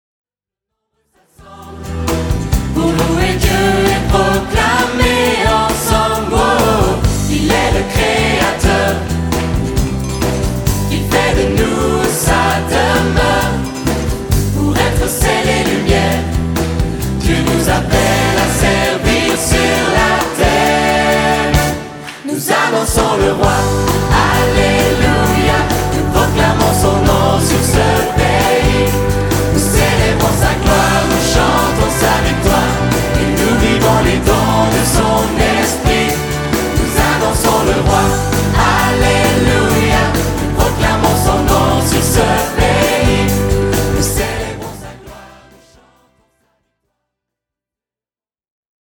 Album de louange en LIVE
Une chorale, des solistes et des musiciens de talent